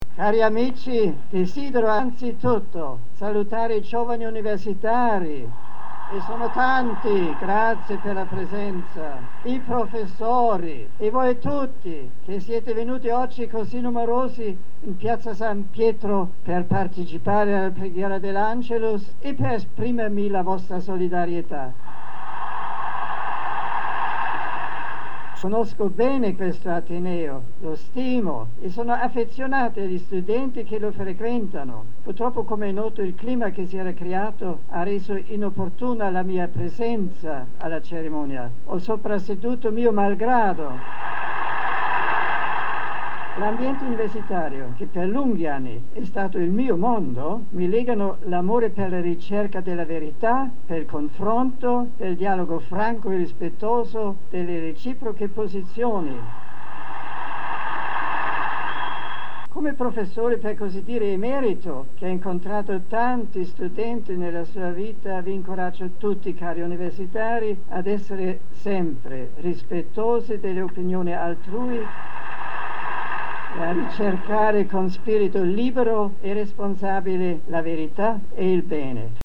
Ascolta le parole di Papa Benedetto XVI di domenica 20 gennaio 2008
Angelus.mp3